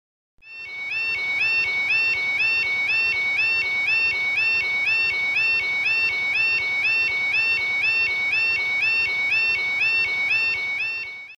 На этой странице собраны звуки железнодорожного переезда: сигнальные звонки, гудки локомотива, стук колес по рельсам.
Сирена жд переезда в Британии